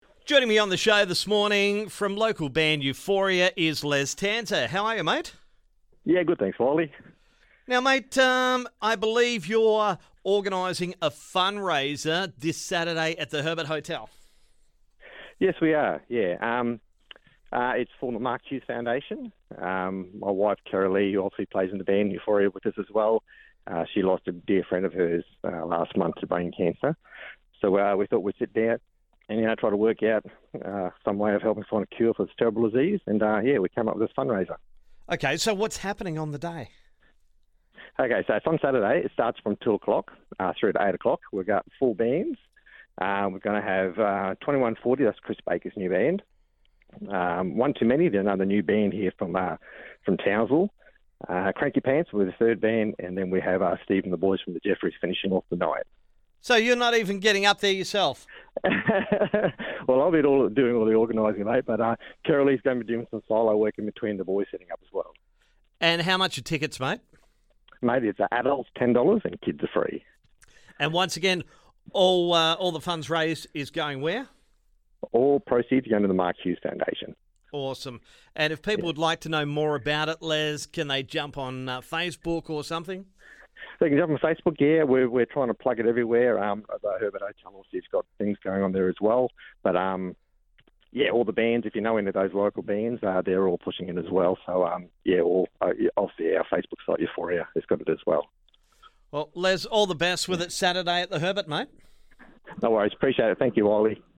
Chatting